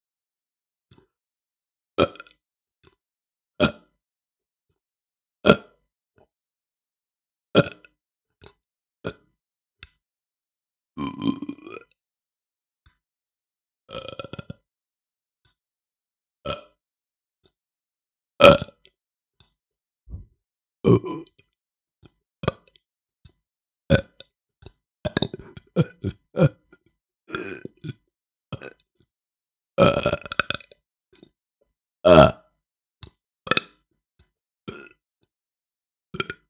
Burp one shots - Burping vocal
Category 🗣 Voices
breathy female funny male mouth sound speaking speech sound effect free sound royalty free Voices